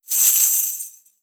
Danza árabe, bailarina da un golpe de cadera con un pañuelo de monedas 01
Sonidos: Acciones humanas